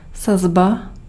Ääntäminen
IPA: [zats]